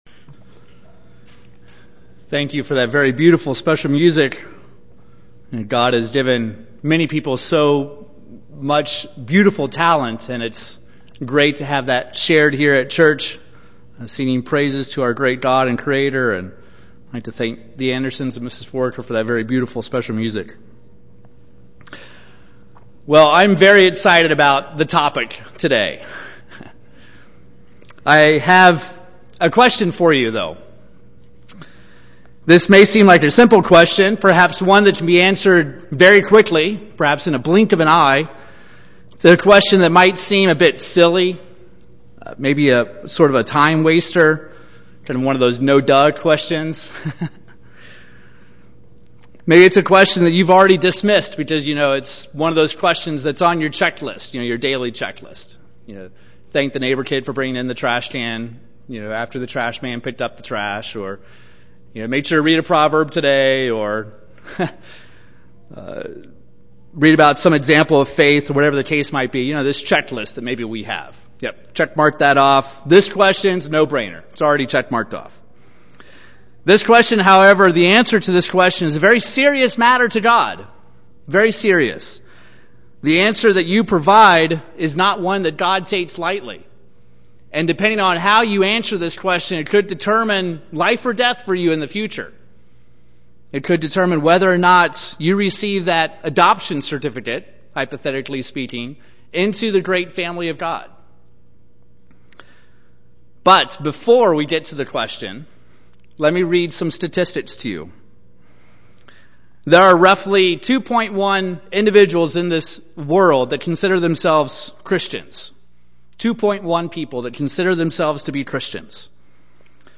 These are the notes taken live during services as captioning for the deaf and hard of hearing.